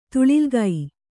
♪ tuḷigai